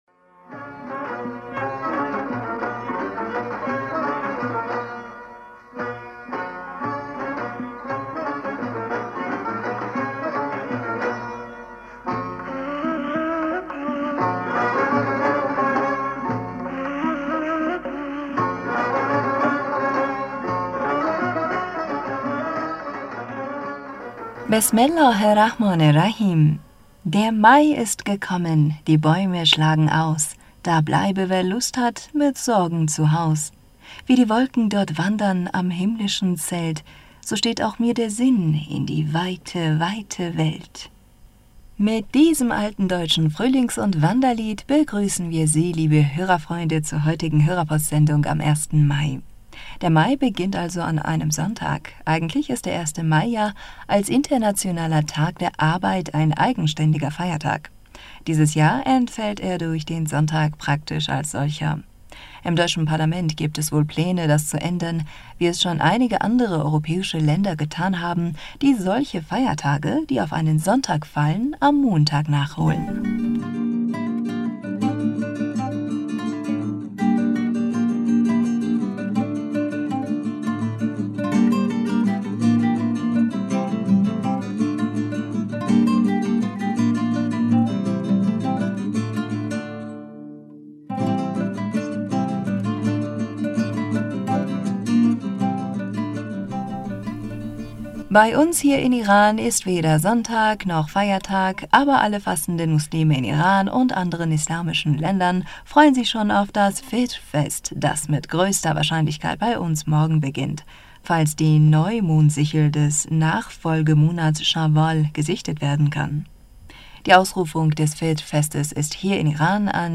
Hörerpostsendung am 1. Mai 2022